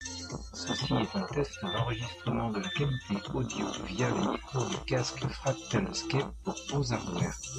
Đáng tiếc, tính năng ENC không có tác dụng gì, thậm chí còn làm suy giảm chất lượng âm thanh. Tiếng bass bị rè và giọng nói của người dùng biến thành một phiên bản robot rất khó chịu.
• [Mẫu âm thanh Micro tích hợp đa hướng, nhạc và bật ENC]